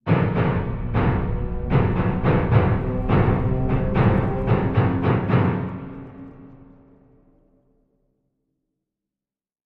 Music Bed; Low Dissonant Piano Clusters For Horror Effect.